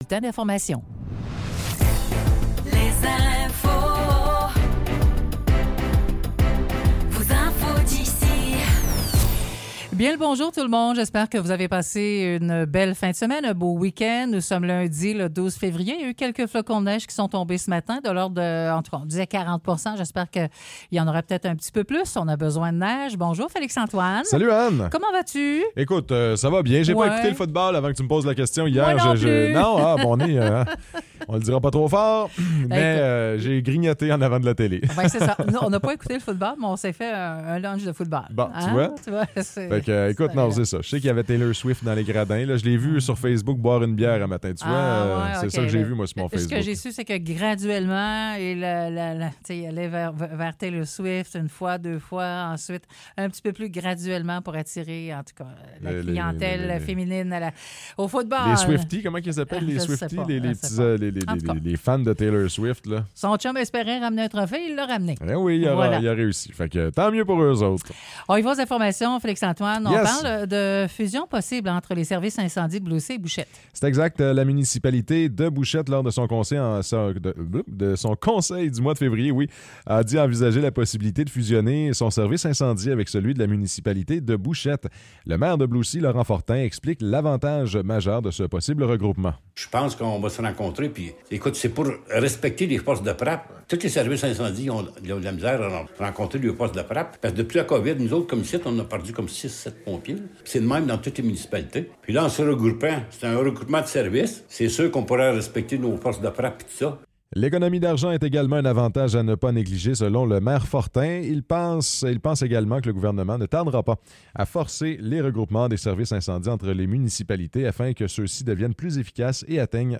Nouvelles locales - 12 février 2024 - 9 h